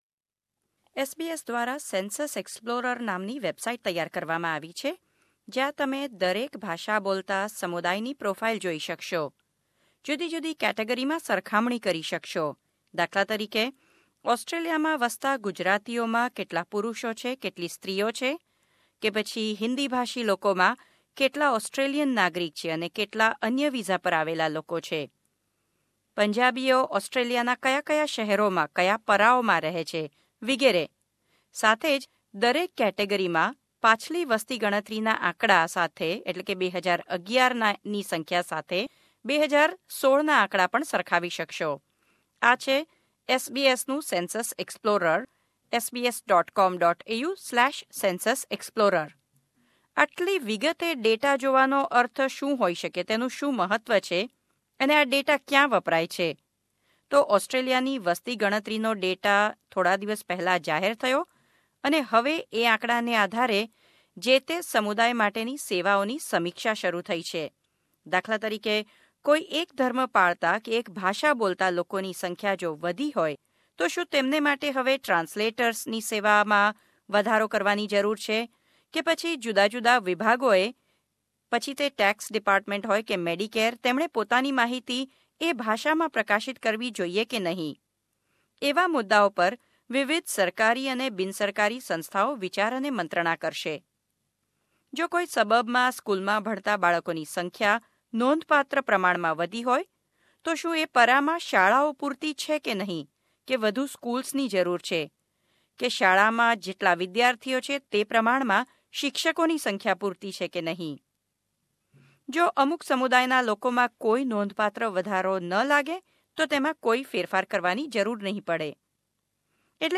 અહેવાલ